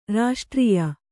♪ raṣṭrīya